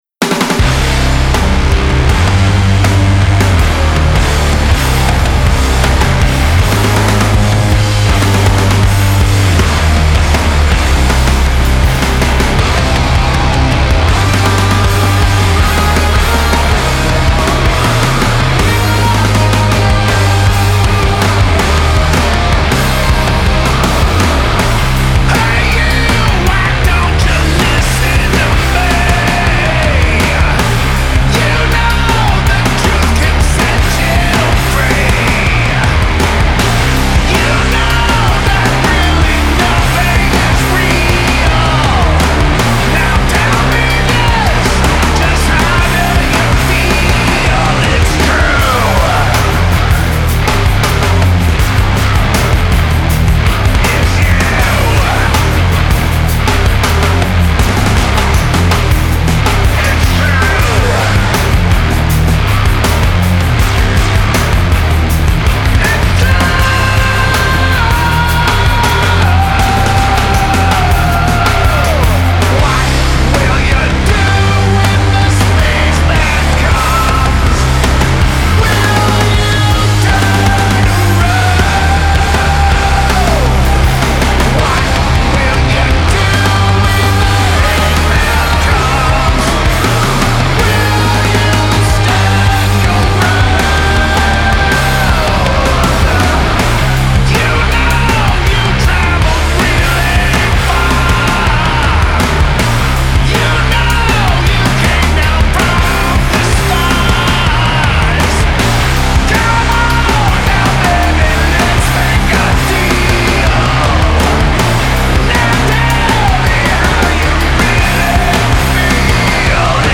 Electro Fuzz / Stoner Metal
heavy and aggressive in all the right ways